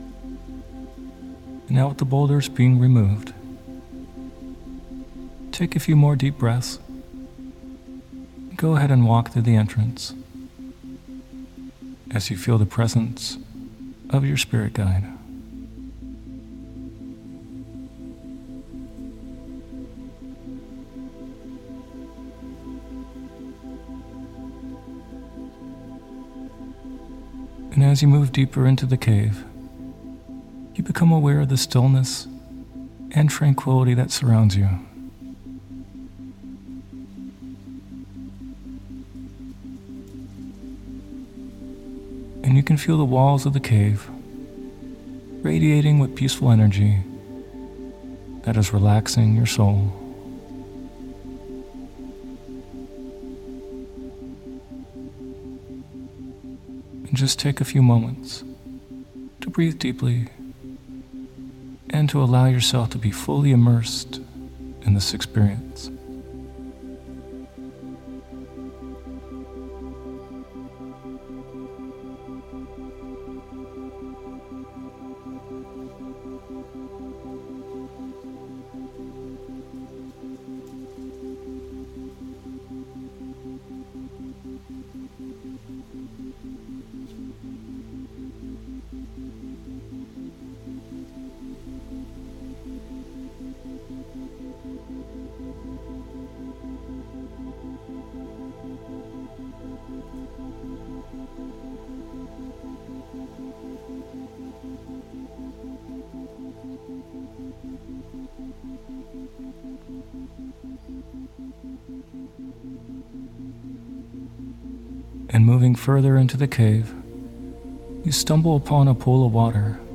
Sleep Hypnosis For Connecting To Your Spirit Guide In A Lucid Dream (Part II)With Isochronic Tones
This guided meditation is going to be an out of this world experience!
This one includes Isochronic Tones.